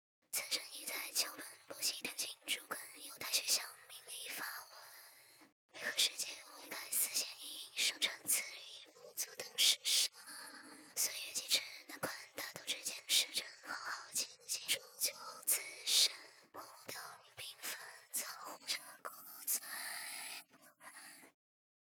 example_aspiration_less_aggr.wav